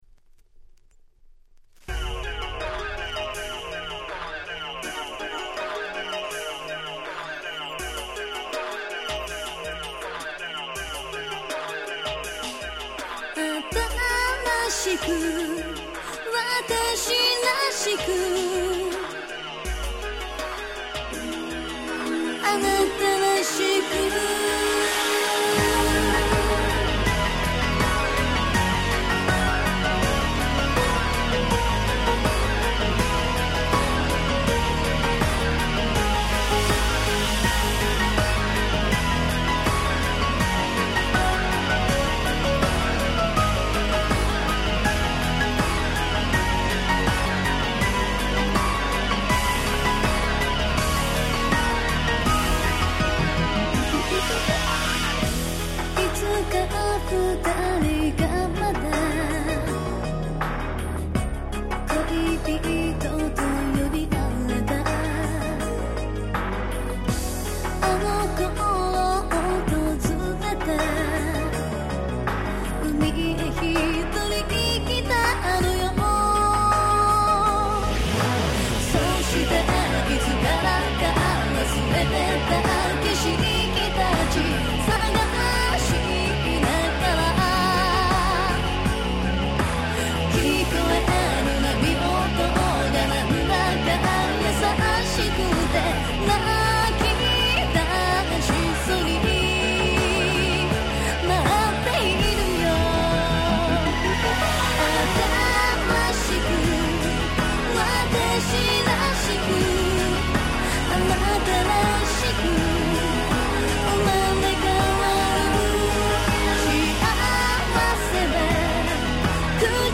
00' Super Hit J-Pop !!